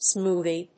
/smúːði(米国英語)/